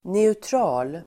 Uttal: [neutr'a:l]